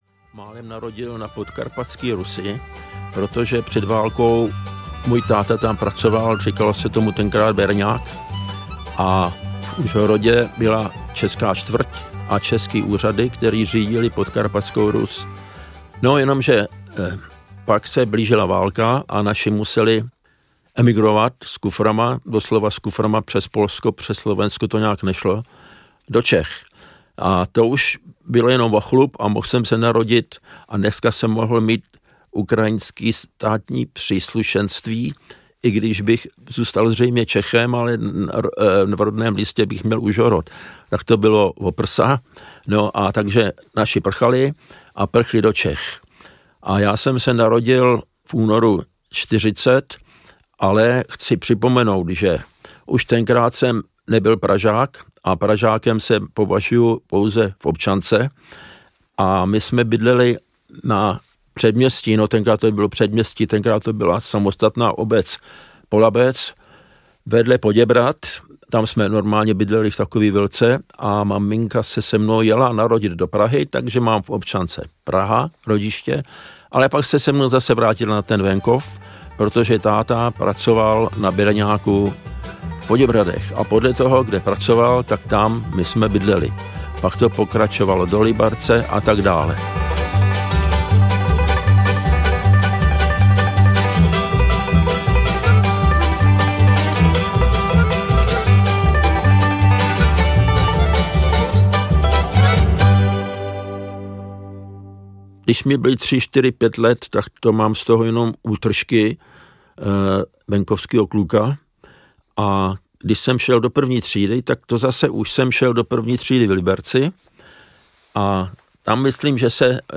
Zvukové vzpomínky legendárního výtvarníka a muzikanta Marko Čermáka Audiokniha pojednává o dlouhé životní cestě kreslíře a ilustrátora Rychlých šípů, který je světově uznávaným hráčem na pětistrunné banjo a byl dlouholetým členem legendární skupiny…
Ukázka z knihy
• InterpretMarko Čermák